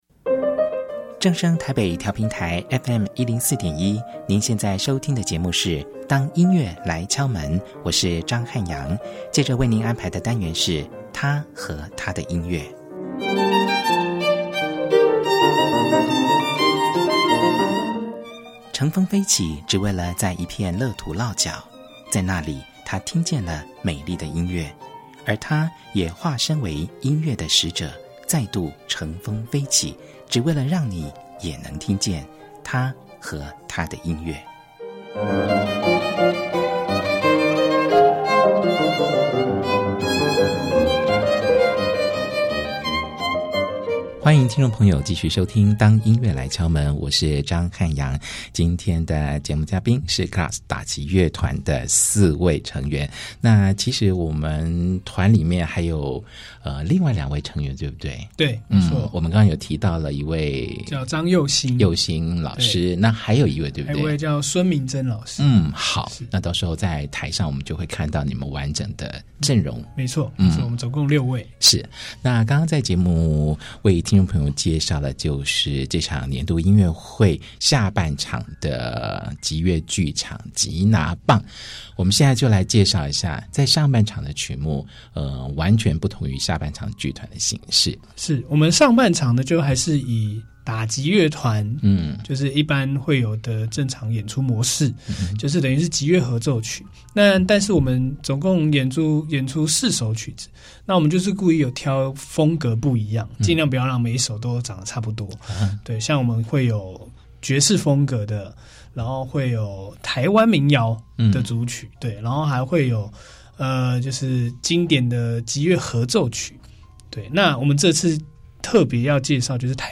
本集節目充滿爆笑的精采對話，歡迎收聽。